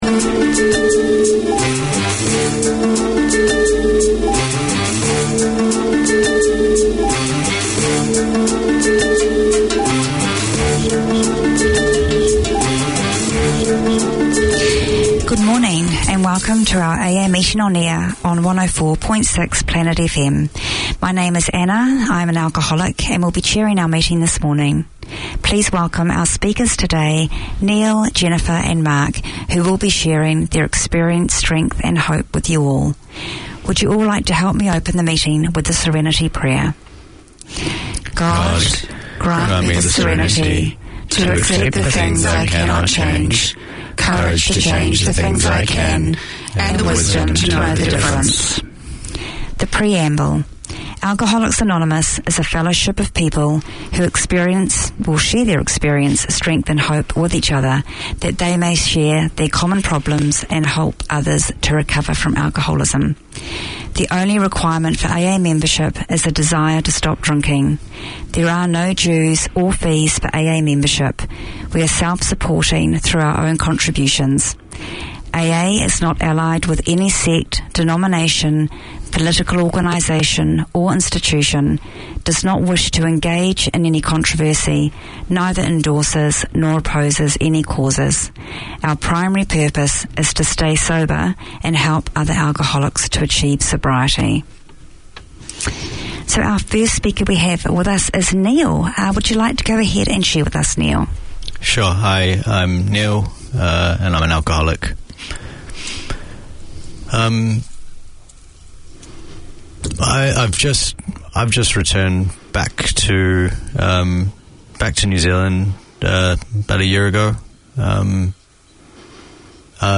Community Access Radio in your language - available for download five minutes after broadcast.
Presented by an experienced English teacher who understands the needs of his fellow migrants, these 30 minute lessons cover all aspects of English including Pronunciation, Speaking, Listening, Reading and Writing. Hear how to improve English for general and workplace communication, social interactions, job interviews, IELTS and academic writing, along with interviews with migrants and English experts.